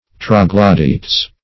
Troglodytes \Trog`lo*dy"tes\, n. [NL.